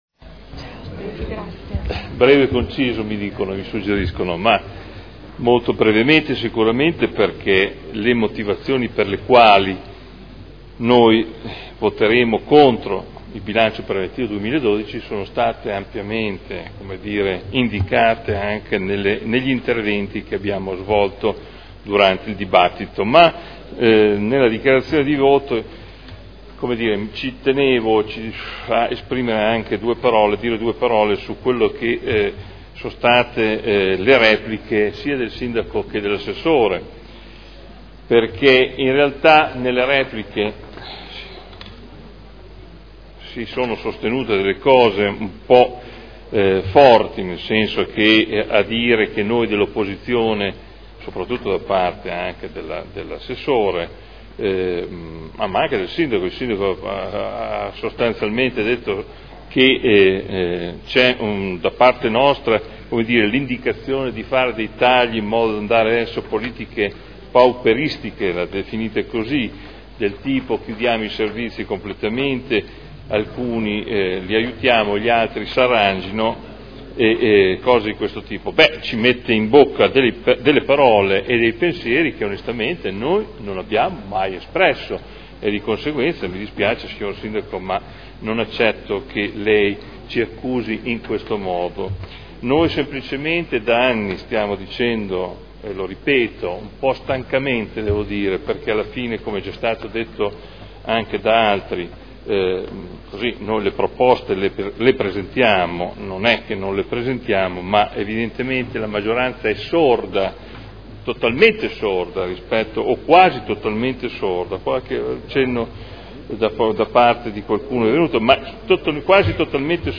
Seduta dell'11 giugno Dichiarazioni di voto sul Bilancio